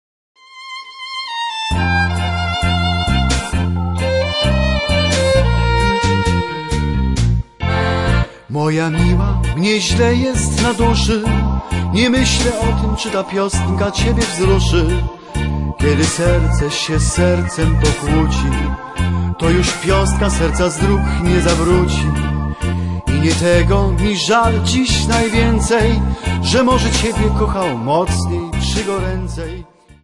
Folk Tangos and Waltzes.